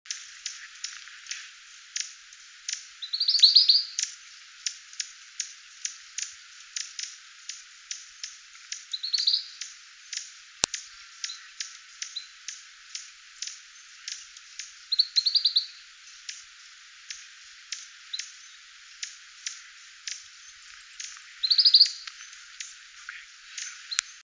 15-4麟趾山口2012march28栗背林鴝m2-c+song.mp3
物種名稱 栗背林鴝 Tarsiger johnstoniae
錄音地點 南投縣 信義鄉 玉山麟趾山口
錄音環境 灌木叢
雄鳥 錄音器材 錄音: 廠牌 Denon Portable IC Recorder 型號 DN-F20R 收音: 廠牌 Sennheiser 型號 ME 67